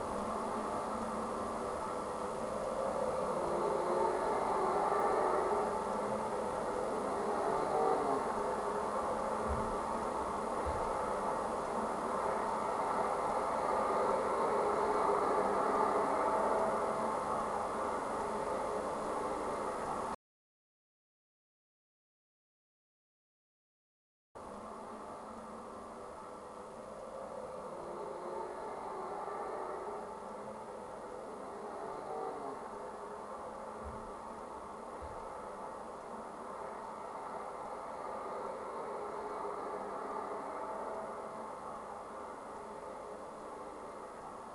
• 交通量の多い幹線道路
サッシのみの場合とソリッドガラスを貼り付けた場合の比較図
道路騒音の低減量 ：約 -6.8ｄＢ
d-soundproof-effect-traffic-noise-01.mp3